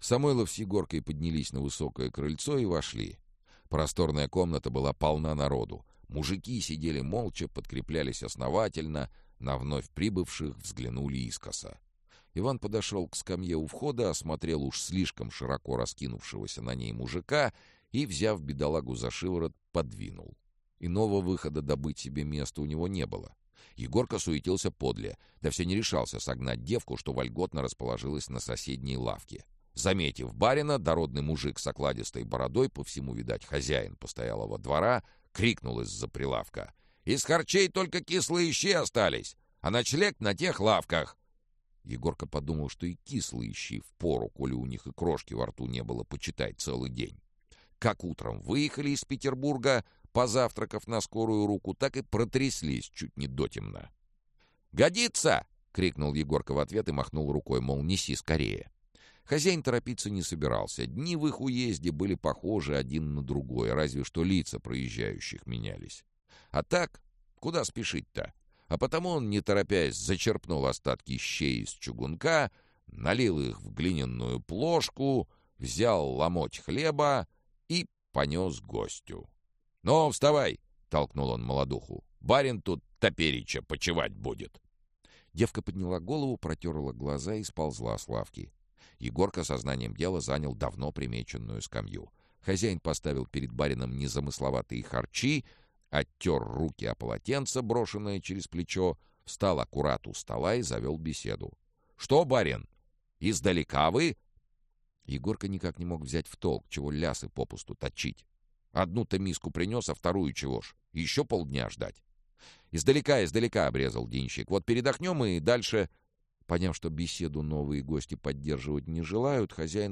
Аудиокнига Записки экспедитора Тайной канцелярии. Проклятая Усадьба | Библиотека аудиокниг
Проклятая Усадьба Автор Олег Рясков Читает аудиокнигу Сергей Чонишвили.